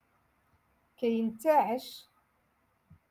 Moroccan Dialect-Rotation Six-Lesson Twenty One